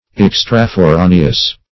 Search Result for " extraforaneous" : The Collaborative International Dictionary of English v.0.48: Extraforaneous \Ex`tra*fo*ra"ne*ous\, a. [Pref. extra- + L. foras out of doors.]
extraforaneous.mp3